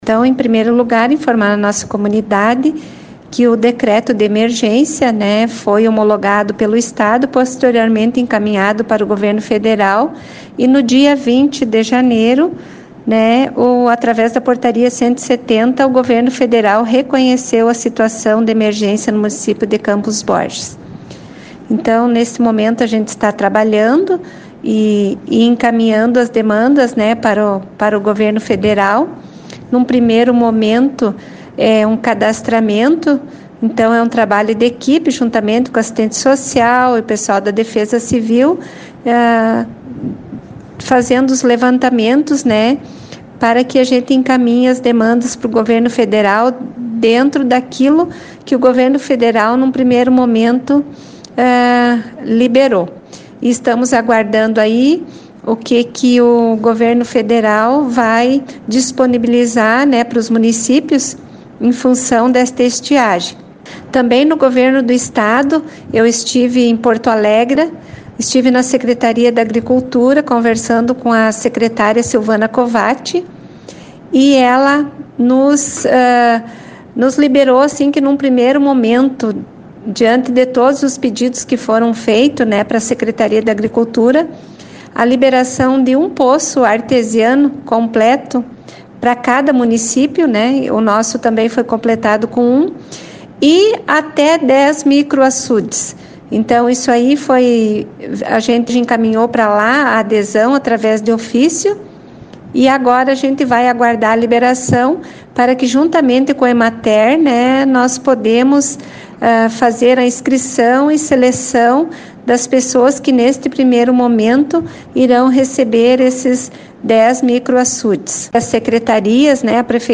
As lavouras de soja, milho e a produção de leite foram fortemente atingidas, conforme destaca a prefeita de Campos Borges, Cleonice Toledo.
Por outro lado, a prefeita de Campos Borges revelou que o município foi contemplado com recursos para diversas áreas, entre elas iluminação do parque de eventos e reformas na Unidade de Saúde.